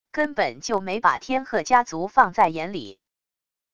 根本就没把天鹤家族放在眼里wav音频生成系统WAV Audio Player